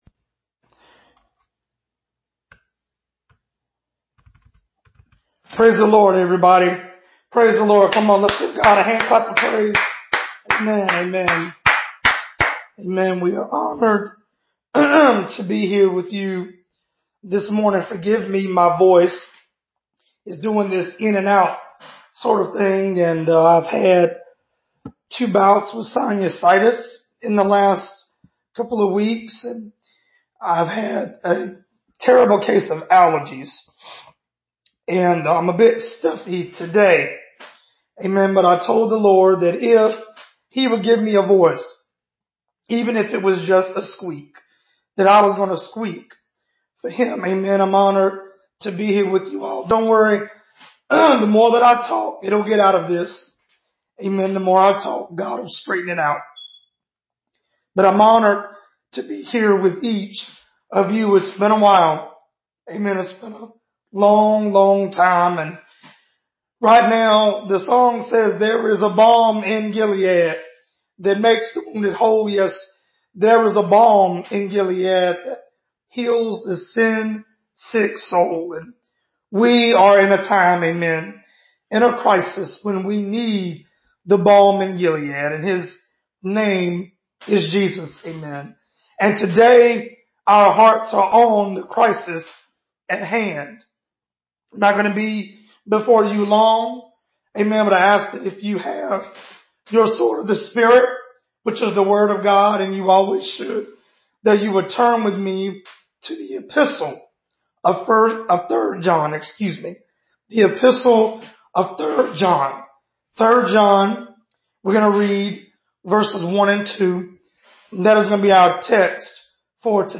Sermon: Prospering In The Pandemic (3 John 1-2)